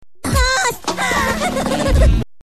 Minion Boss sound effect download for free mp3 soundboard online meme instant buttons online download for free mp3